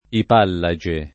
ipallage [ ip # lla J e ]